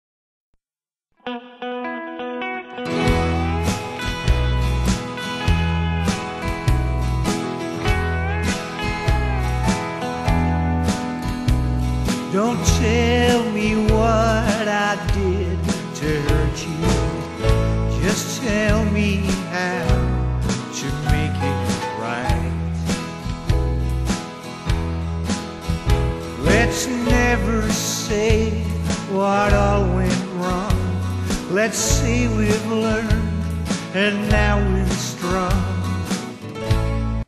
in a Country style